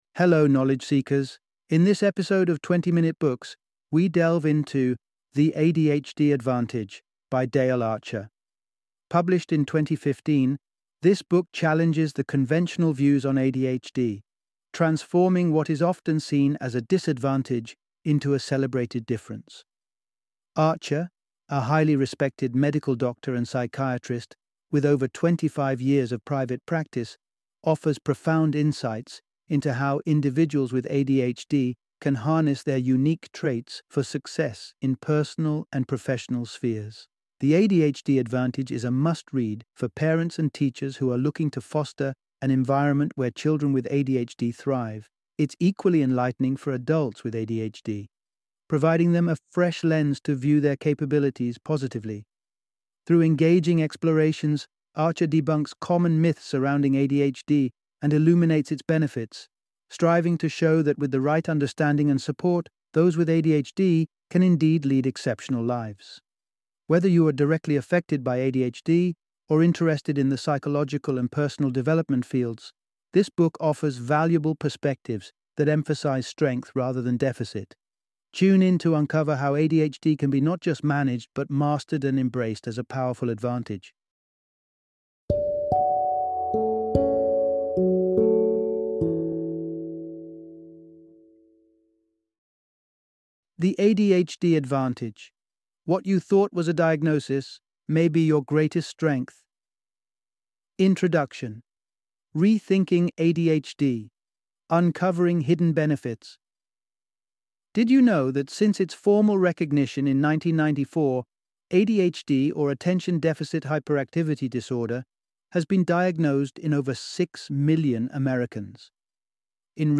The ADHD Advantage - Audiobook Summary